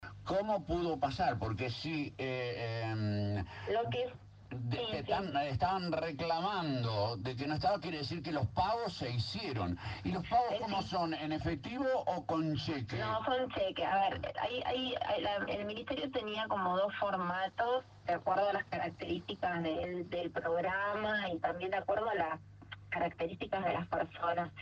La vicegobernadora Laura Stratta eligió Radio LT 39 de Victoria para intentar minimizar el escándalo de los subsidios truchos que la tiene como protagonista y que ha generado un enorme escándalo en la ciudad de Victoria y en toda la Provincia.
Entrevista-a-Stratta-2.mp3